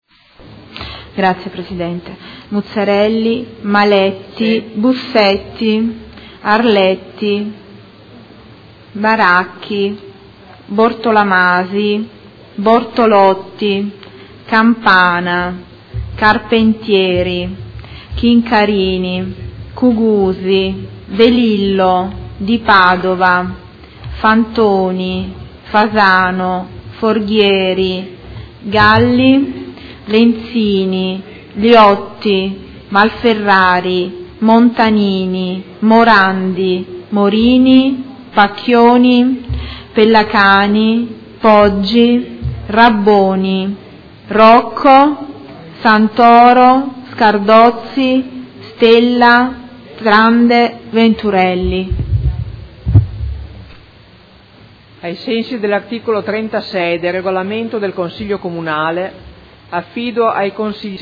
Seduta del 3/12/2015. Appello
Segretario Generale